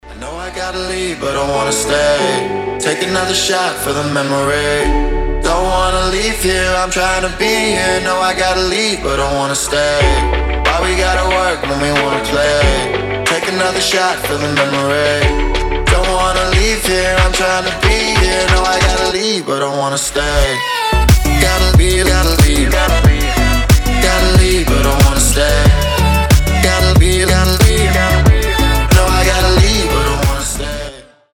• Качество: 320, Stereo
мужской голос
Midtempo